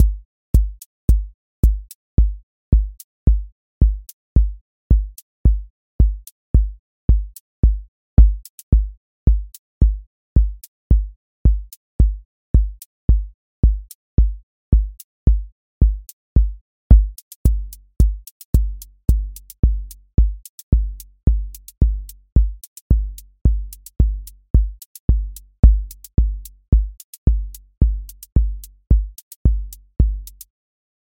QA Listening Test house Template: four_on_floor
steady house groove with lift return
• voice_kick_808
• voice_hat_rimshot
• voice_sub_pulse